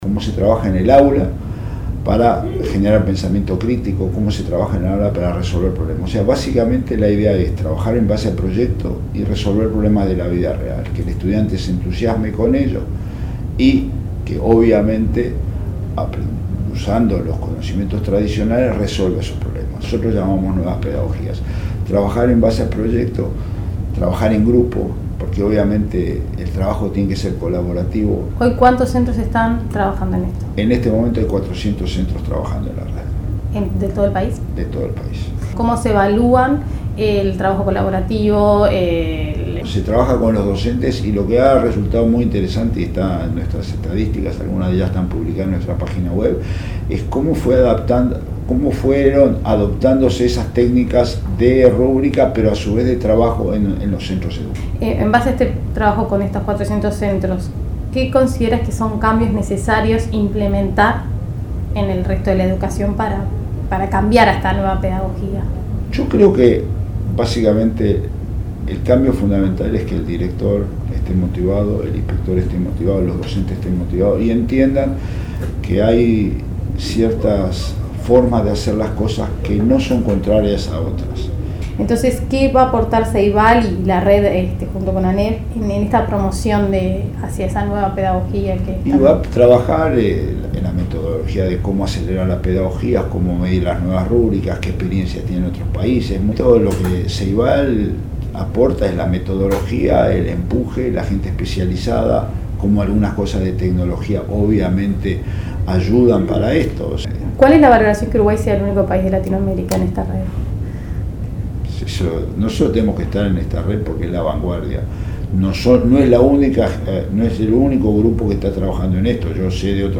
Se trata de generar pensamiento crítico para que los estudiantes resuelvan problemas de la vida real en base a proyectos, explicó el director del Plan Ceibal, Miguel Brechner. Dijo que para el futuro se propone consolidar la red y aumentar los centros que se adhieran.